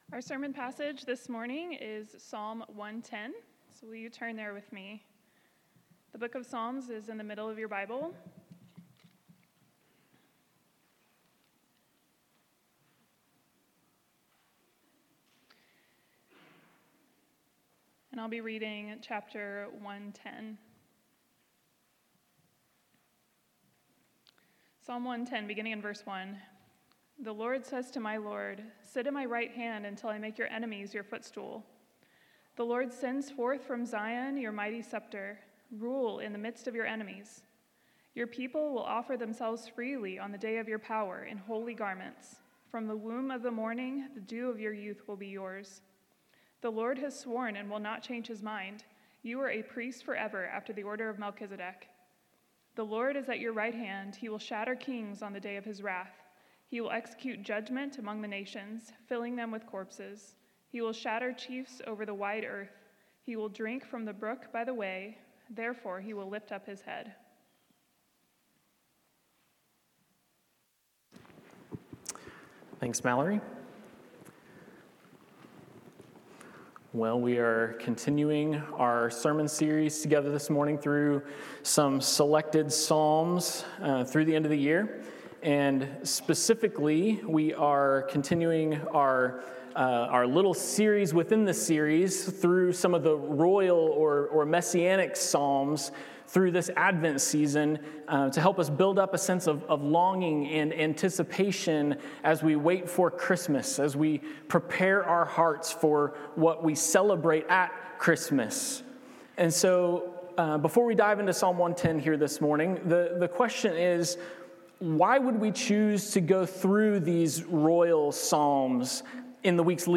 Born a Child and Yet a King-Priest | Sermons | Cross Fellowship Church